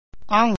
awng